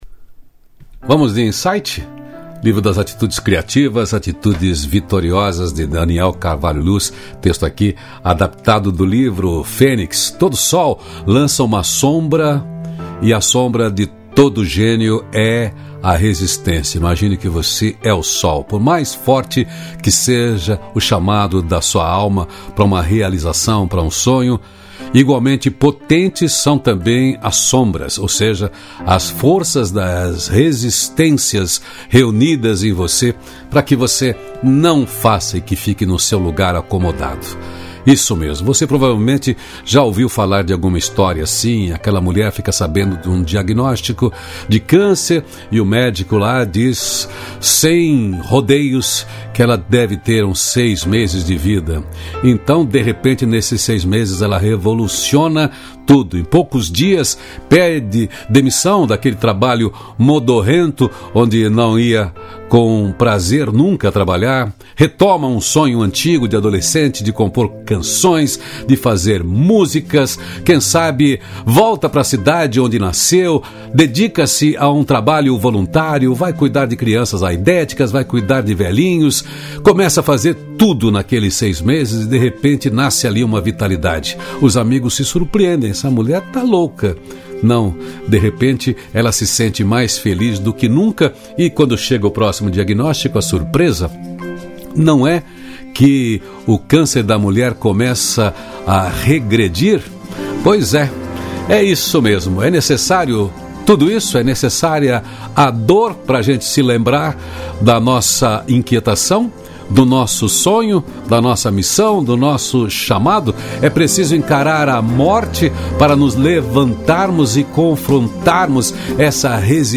Música: Não há pedras no caminho… Banda Aquática Álbum: Nova Manhã